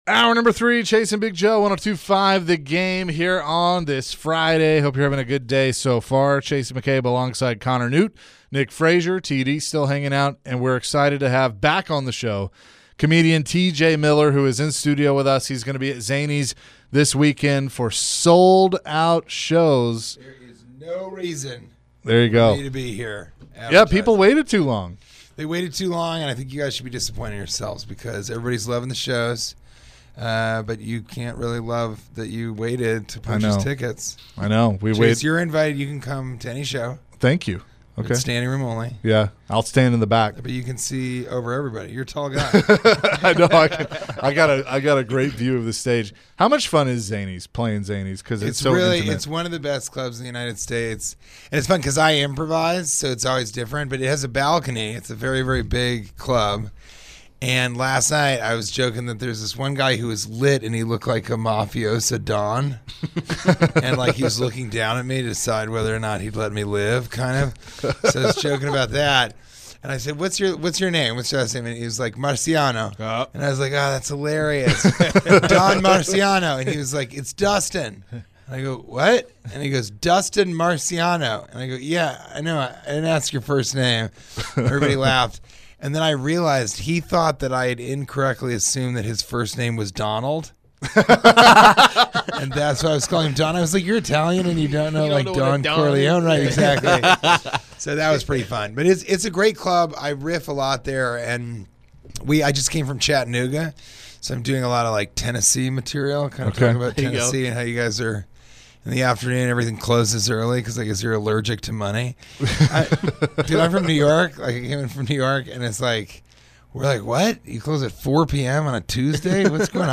Comedian TJ Miller joined the show in the studio and shared some laughs ahead of his comedy show at Zanies this weekend.